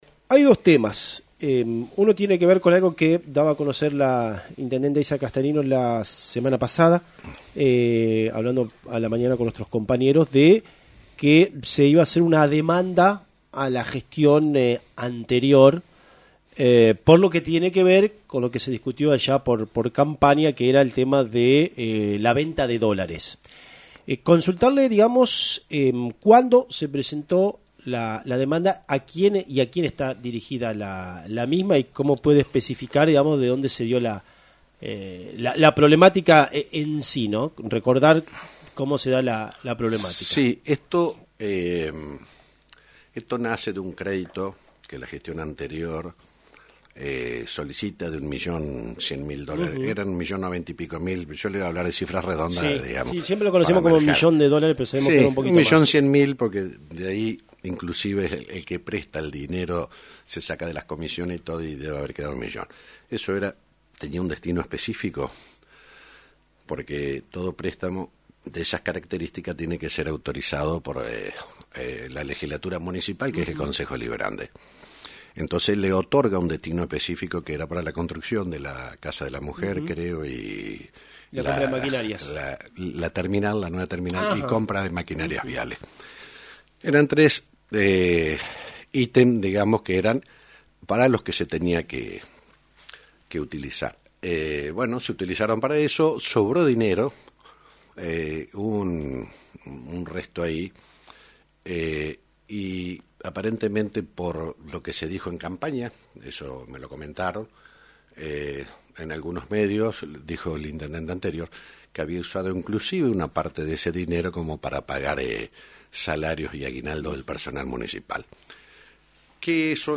proporcionó detalles en el programa “Radionoticias” de FM 90.3